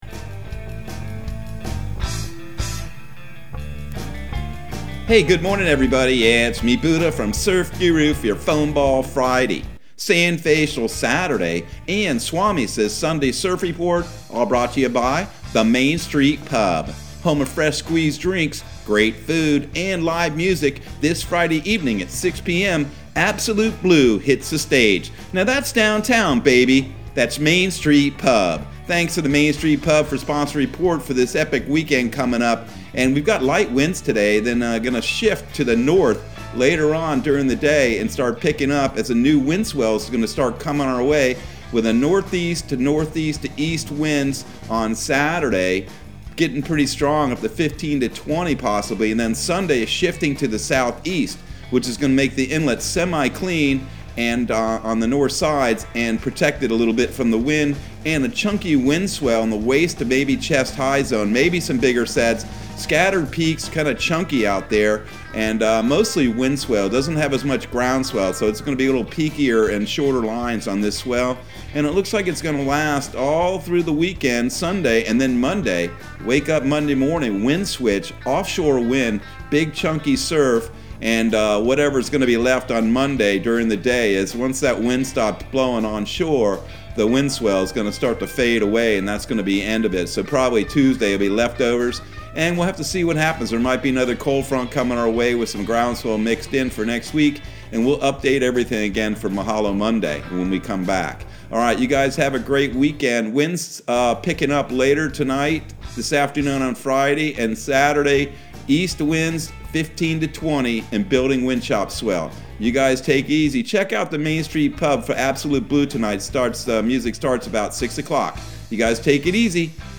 Surf Guru Surf Report and Forecast 01/07/2022 Audio surf report and surf forecast on January 07 for Central Florida and the Southeast.